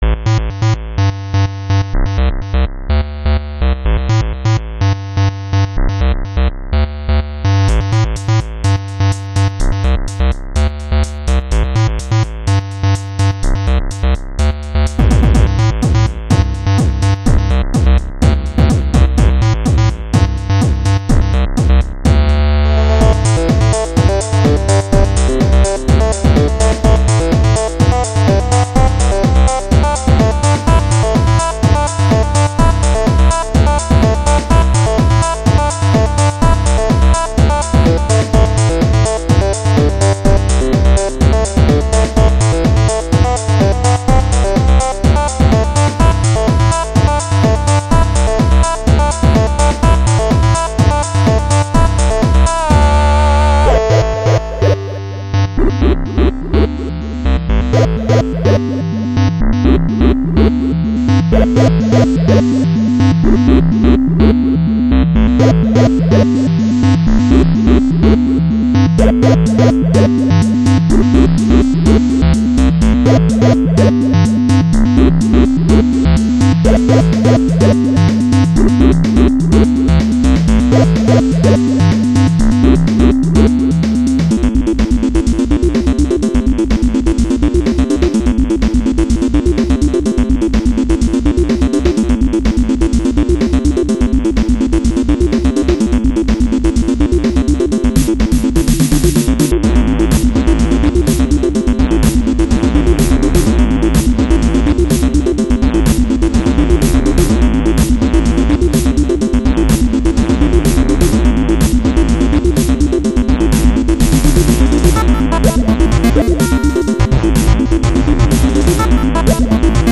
Retro menu music for an action game.
• Chip music
• Music has an ending (Doesn't loop)